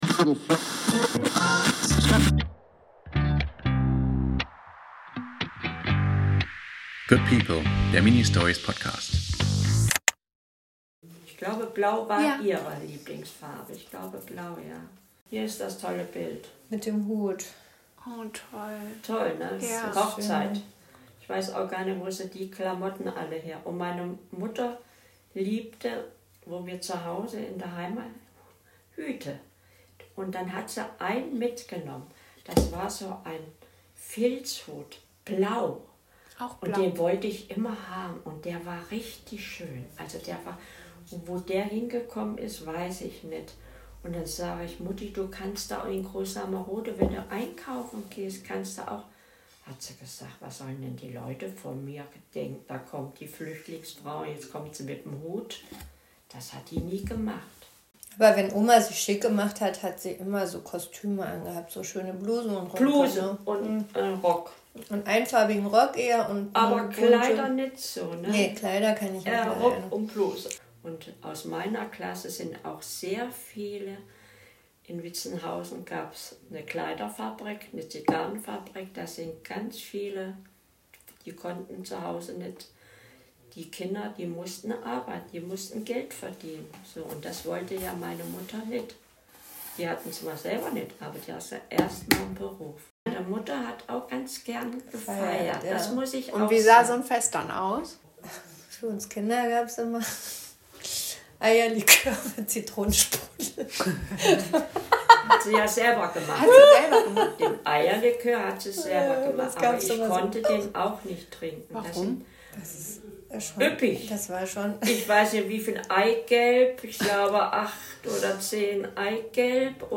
Dabei entsteht das akustische Mosaik einer Frau, bei der viele gerne zu Besuch waren.